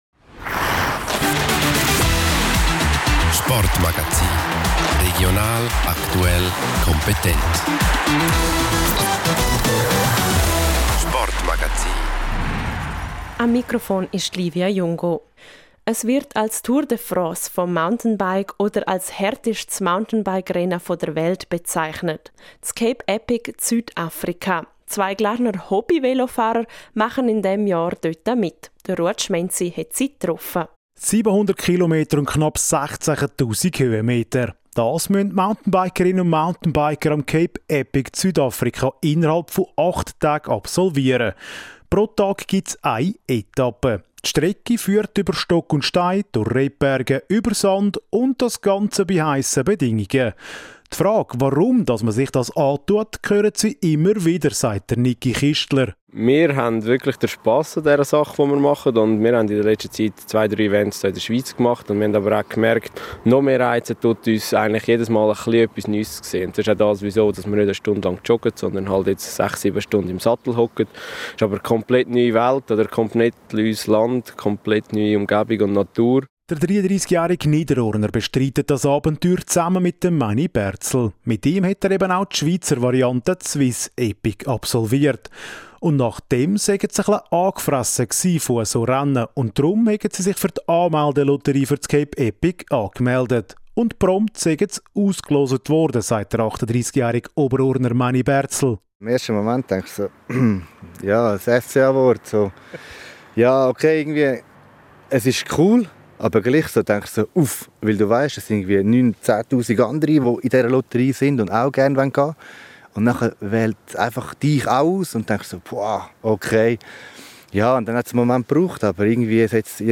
Das ganze Interview